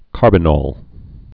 (kärbə-nôl, -nŏl, -nōl)